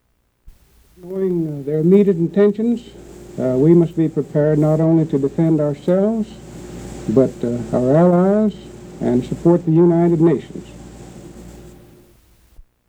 Broadcast on CBS, Oct. 12, 1949.